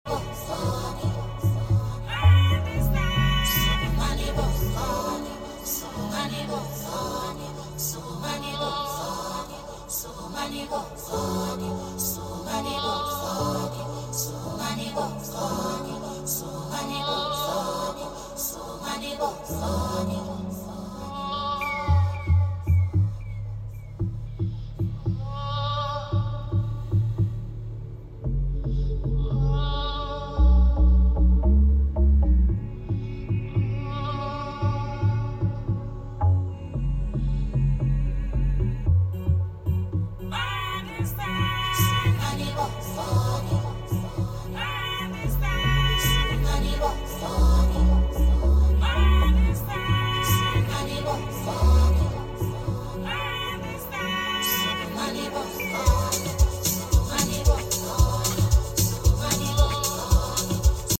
S1k Sounds Mean At Idle👹….